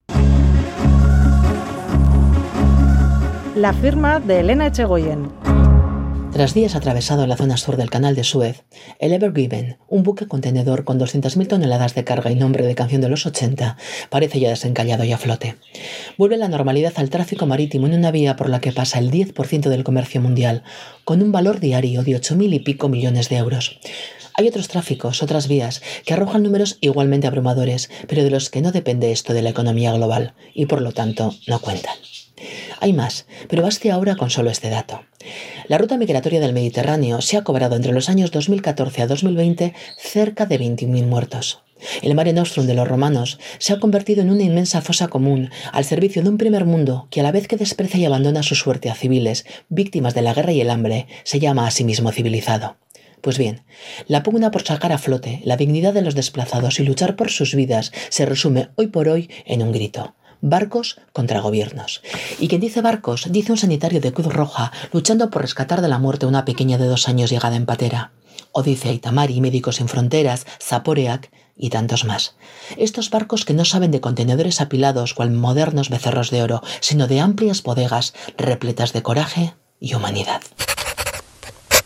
Audio: Columna de opinión en Boulevard de Radio Euskadi. La abogada y ex senadora Elena Etxegoyen analiza y reflexiona sobre un asunto de actualidad de esta jornada